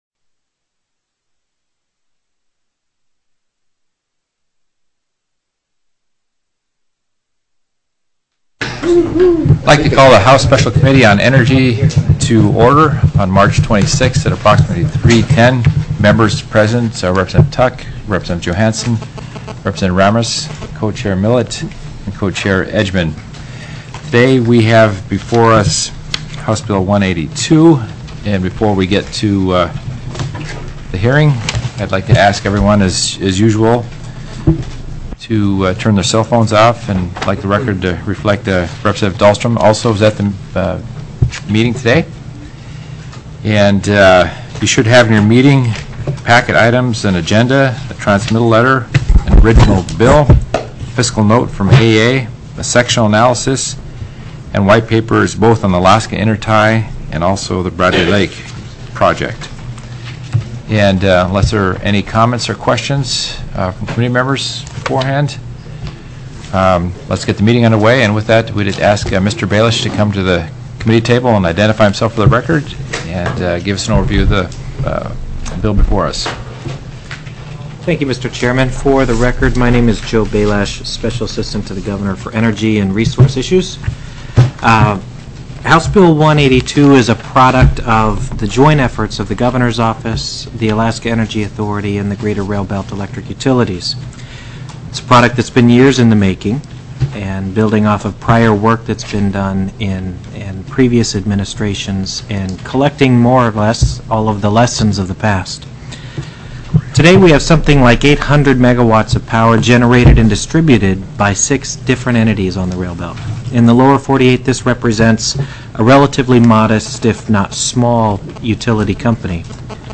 HB 182 RAILBELT ENERGY & TRANSMISSION CORP. TELECONFERENCED Heard & Held
ALASKA STATE LEGISLATURE HOUSE SPECIAL COMMITTEE ON ENERGY